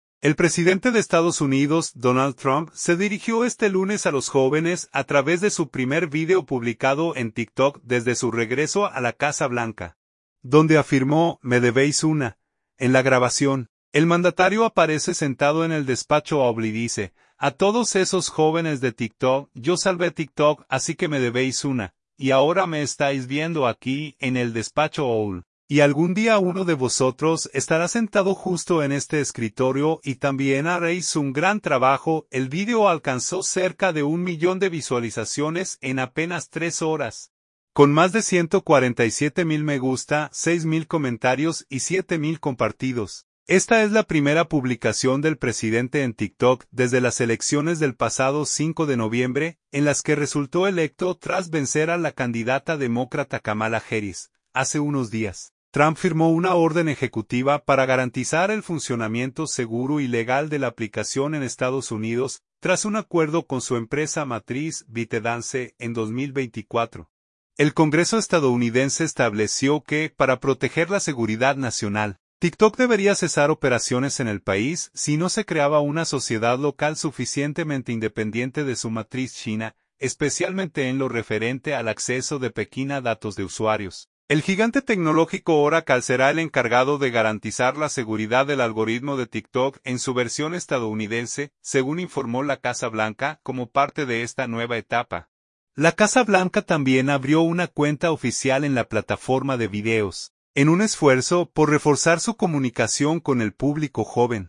En la grabación, el mandatario aparece sentado en el Despacho Oval y dice: “A todos esos jóvenes de TikTok, yo salvé TikTok, así que me debéis una. Y ahora me estáis viendo aquí, en el Despacho Oval, y algún día uno de vosotros estará sentado justo en este escritorio y también haréis un gran trabajo”.
♬ original sound – President Donald J Trump